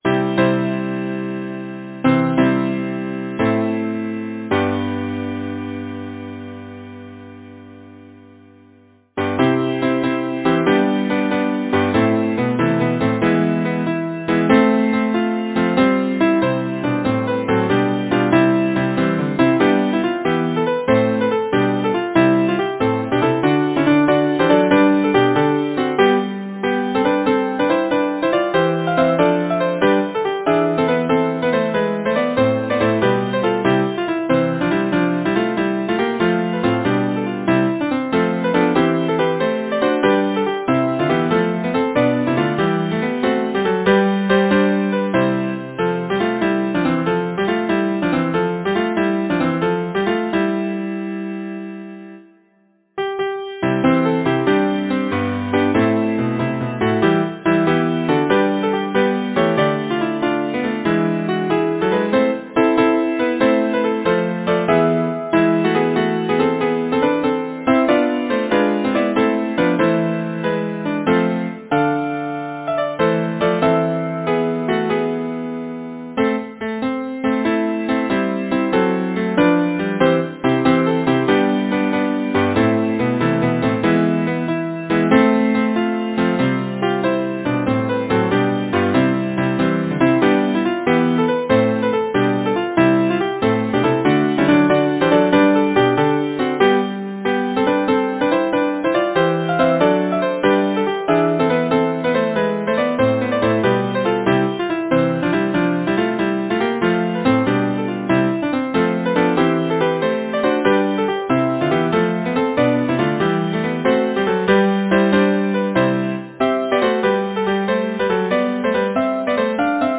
Title: Jog on the footpath way Composer: Clara Angela Macirone Lyricist: William Shakespeare Number of voices: 4vv Voicing: SATB Genre: Secular, Partsong
Language: English Instruments: A cappella